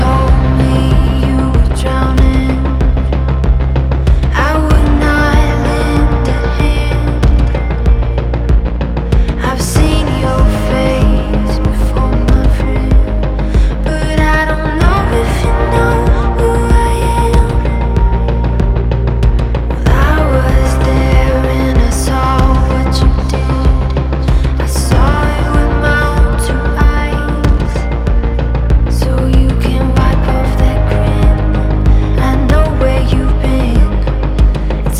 Singer Songwriter